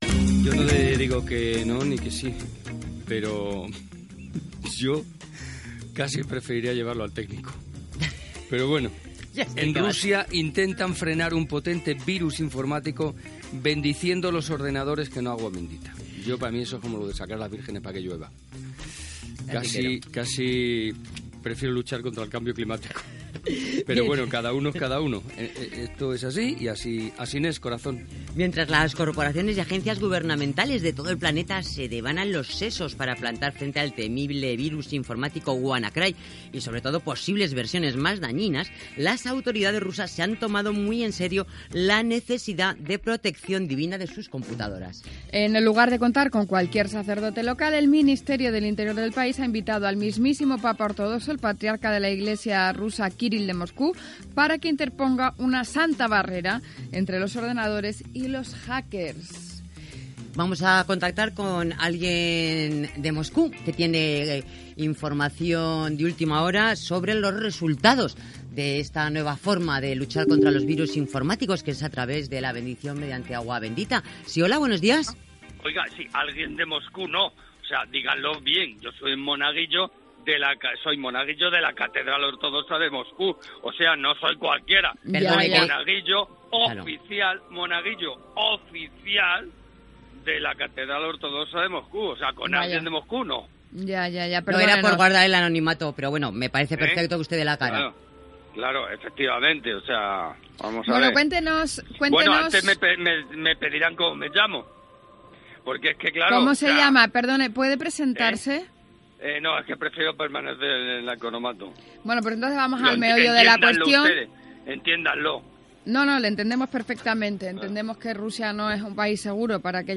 Comentari sobre els virus informàtics a Rússia i "sketch".
Entreteniment